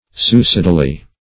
-- Su"i*ci`dal*ly , adv.